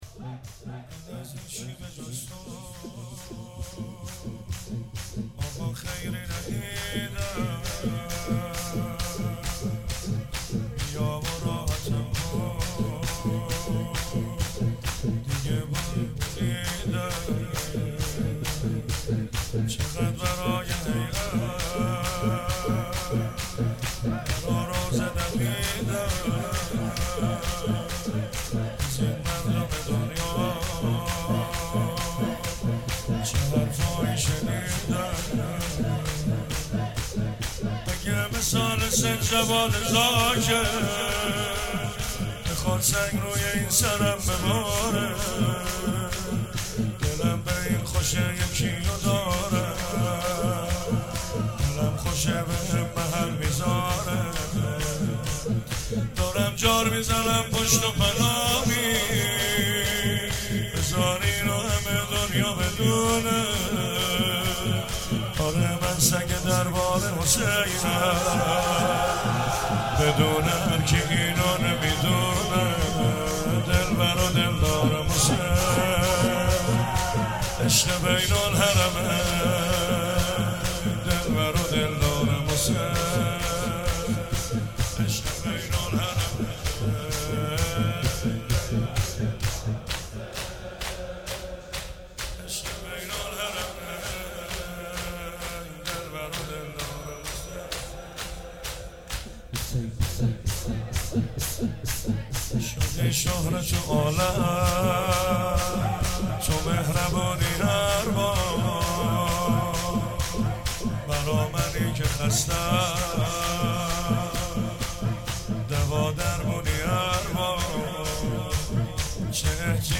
شب سوم رمضان 95، حاح محمدرضا طاهری
06 hiate alamdar mashhadalreza.MP3